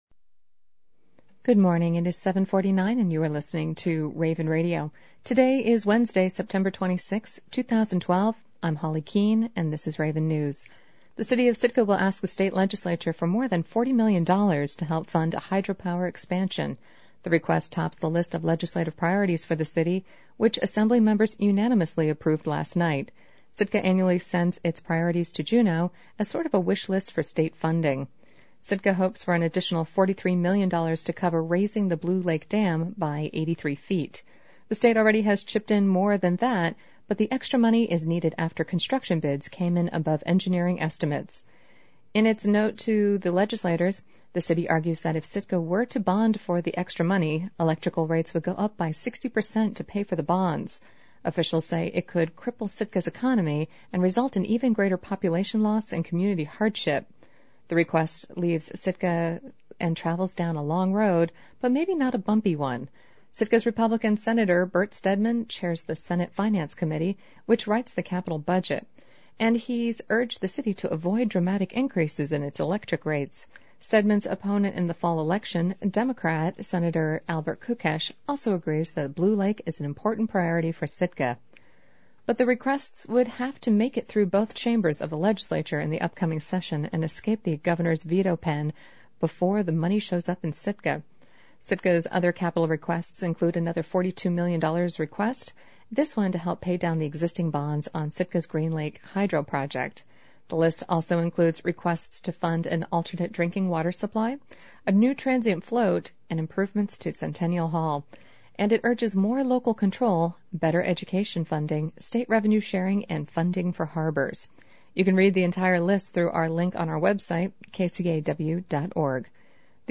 latest_newscast